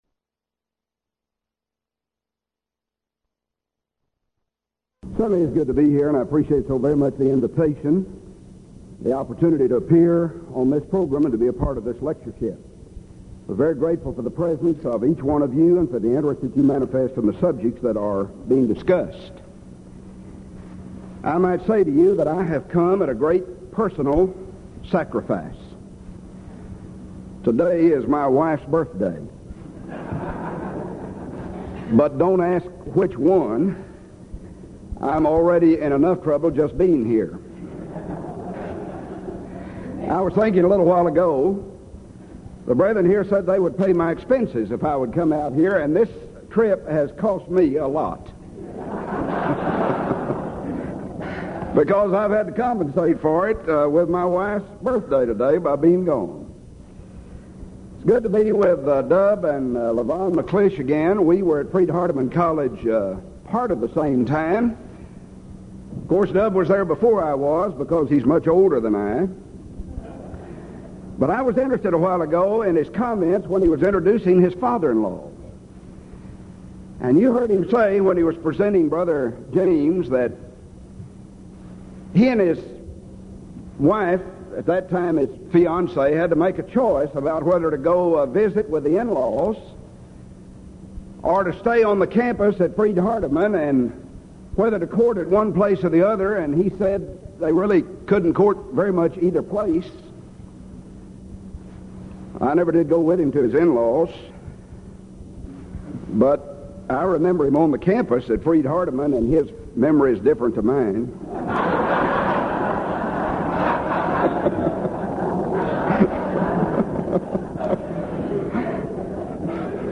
Title: DISCUSSION FORUM: "The Teaching Of Christ" II John 9, Fellowship And Unity
Series: Denton Lectures Event: 1987 Denton Lectures Theme/Title: Studies In I, II, III John